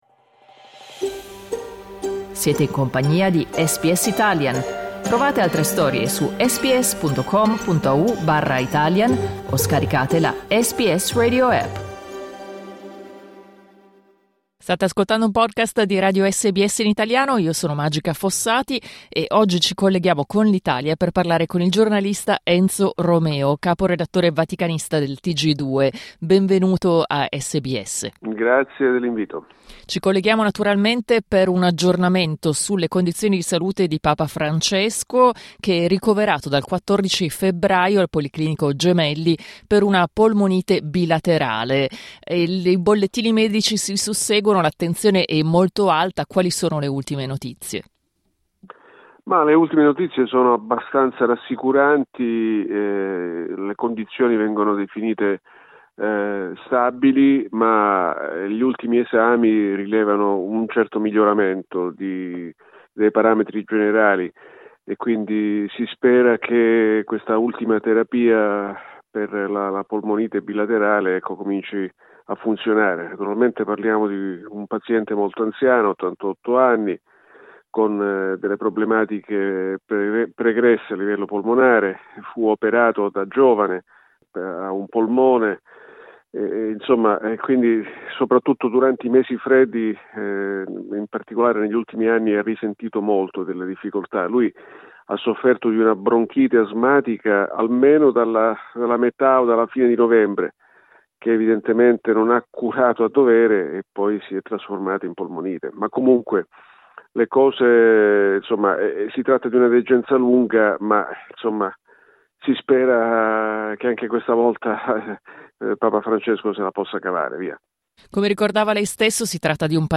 intervistato al microfono di SBS Italian